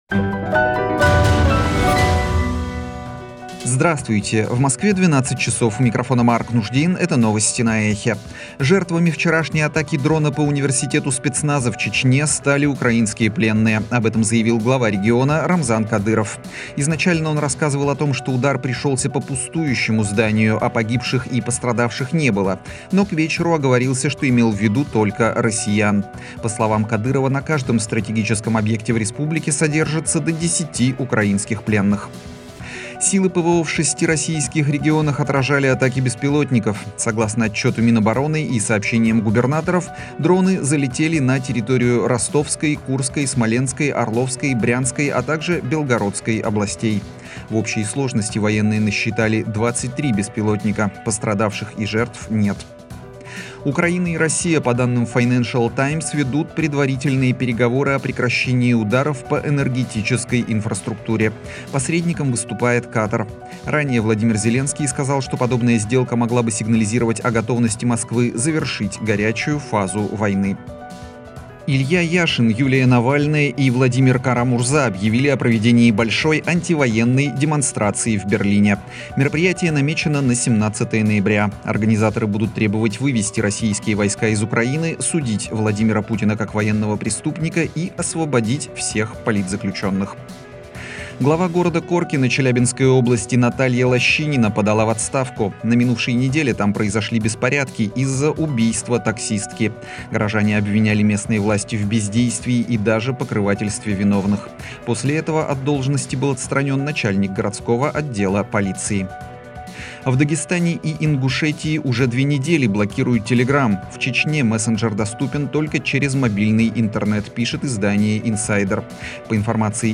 Новости - ЭХО
Слушайте свежий выпуск новостей «Эха»